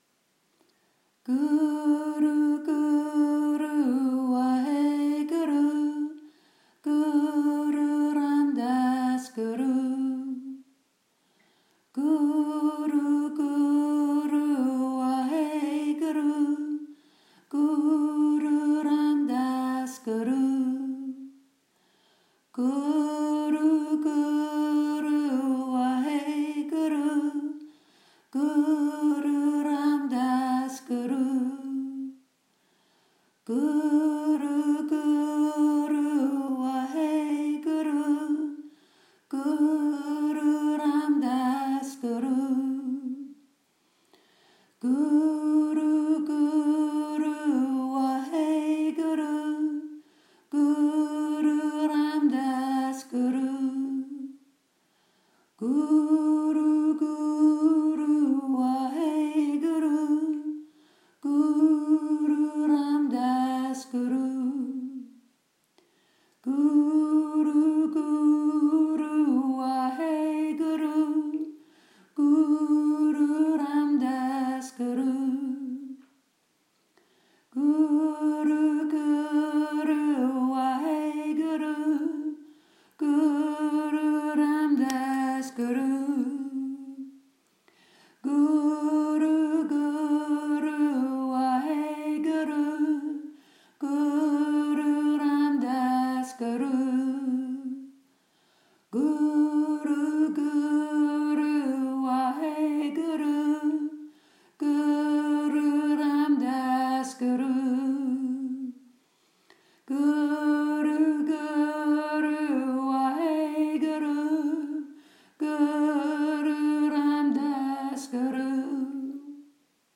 Mantra Celebration
l’abstraction du mental créer par la repetition du son rythmique créer l’espace pour tous types de guérison.